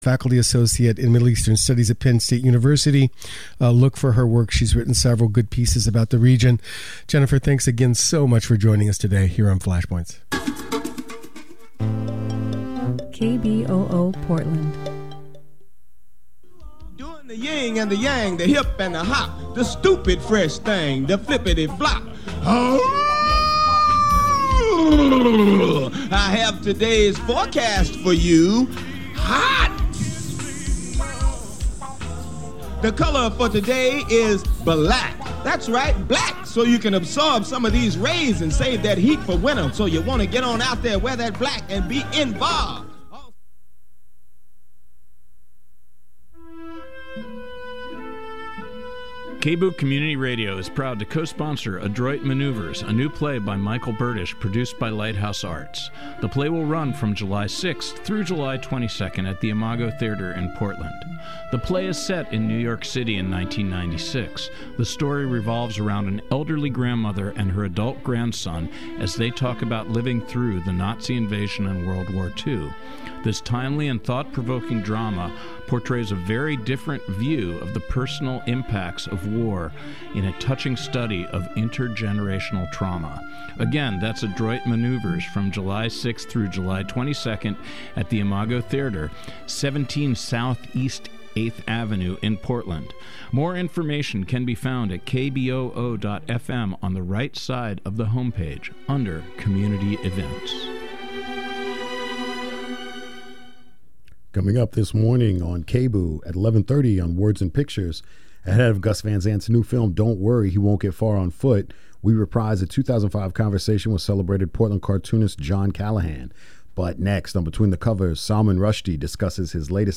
Every third Thursday from 11:00 AM to 12:00 PM A weekly show featuring interviews with locally and nationally known authors of both fiction and non-fiction.